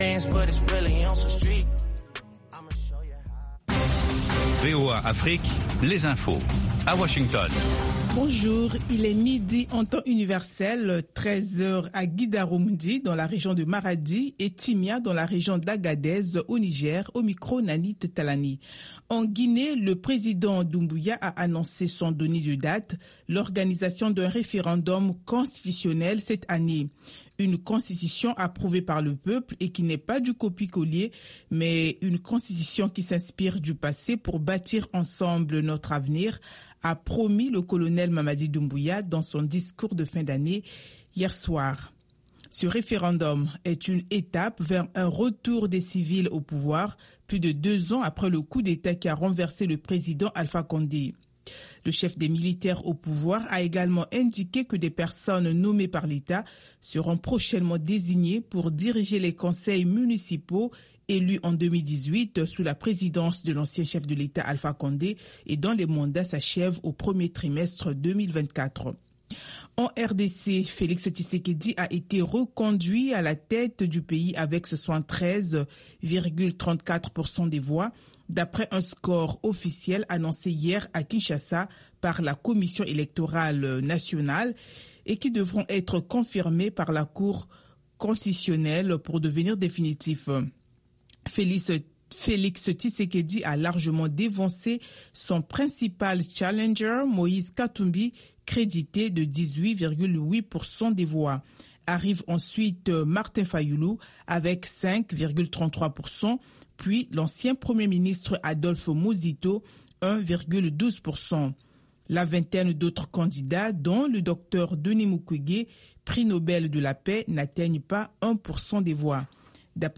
Bulletin d'information de 16 heures
Bienvenu dans ce bulletin d’information de VOA Afrique.